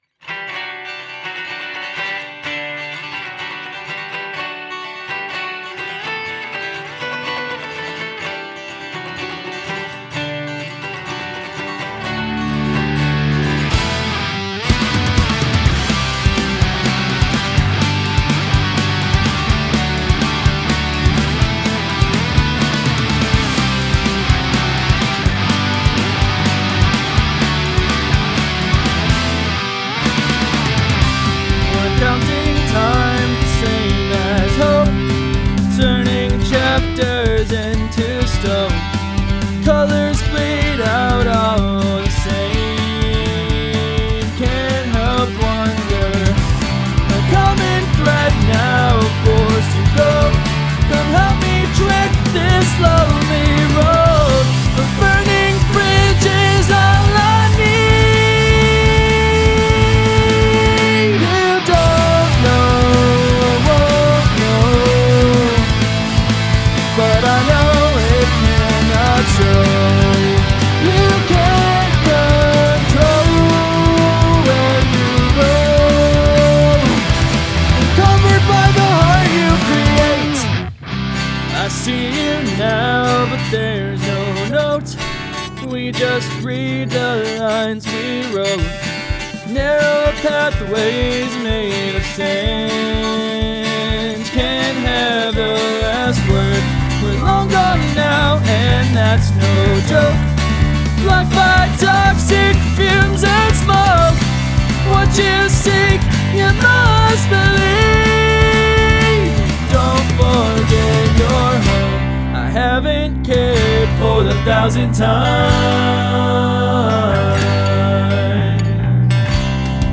Looking for feedback on mixing/mastering.
I get what is being tried with the acoustics, it might be a matter of panning them too far L/R and not also applying a bit of stereo panning to them as well, so they're not purely only sitting in the opposing channels (look up/google stereo panning for ideas). The vocal is a bit dry, and could use some stereo panning/chorus as well. Give it a bit more life than mono/sitting stagnant in the middle.